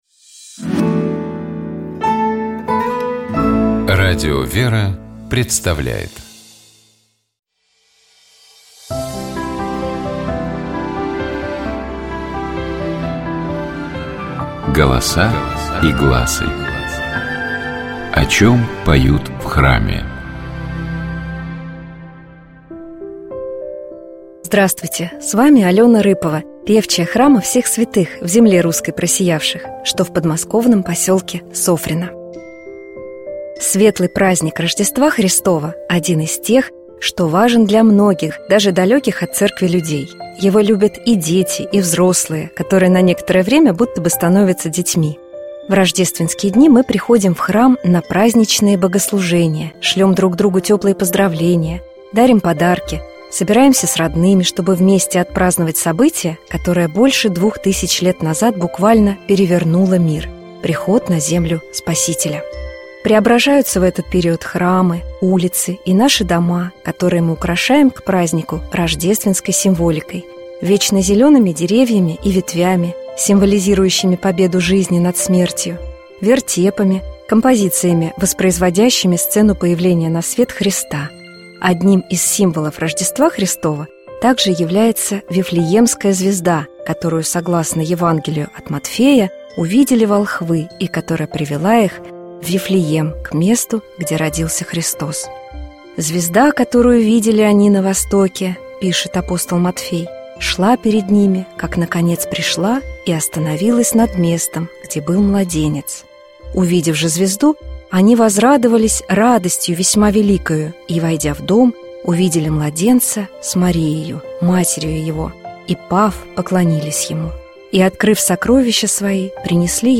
Давайте послушаем тропарь Рождества Христова полностью в исполнении сестёр храма Табынской иконы Божией Матери.